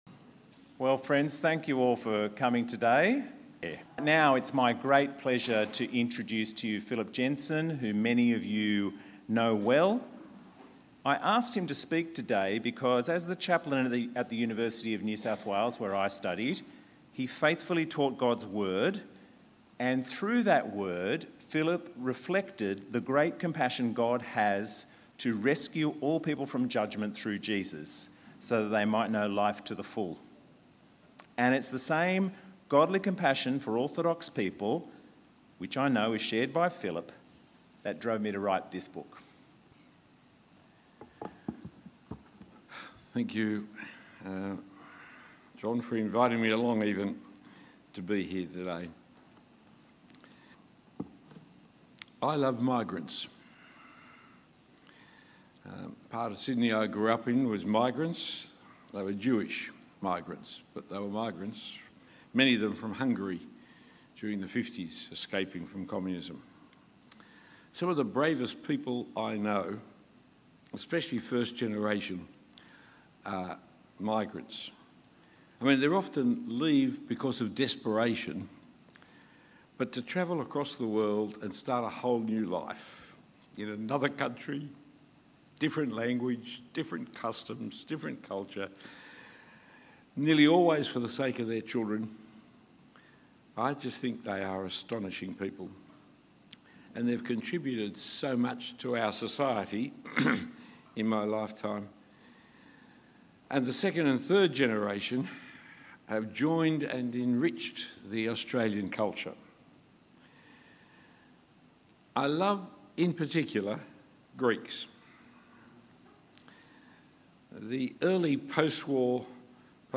Book Launch - Orthodoxy or Certainty